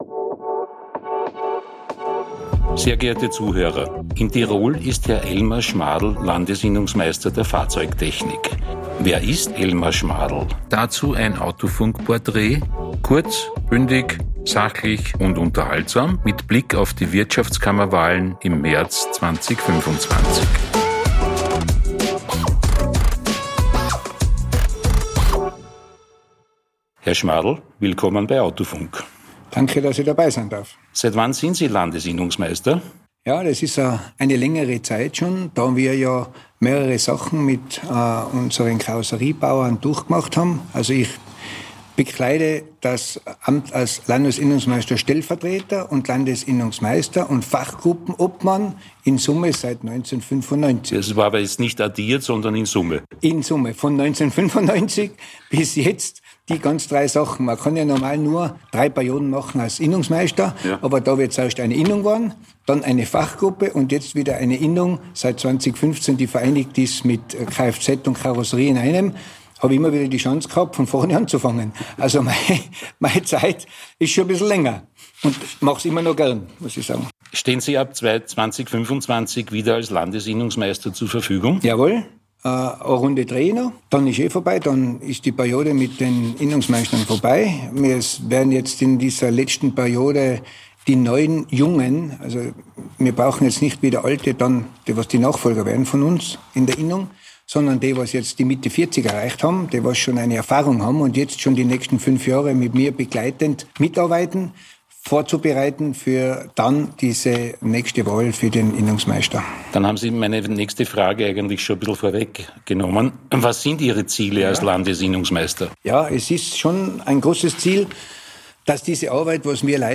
Kurz - Bündig - Sachlich - Unterhaltsam In der Sonderserie